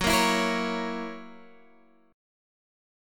Gb7 Chord
Listen to Gb7 strummed